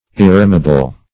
Search Result for " irremeable" : The Collaborative International Dictionary of English v.0.48: Irremeable \Ir*re"me*a*ble\, a. [L. irremeabilis; pref. ir- not + remeabilis returning, fr. remeare: cf. F. irr['e]m['e]able.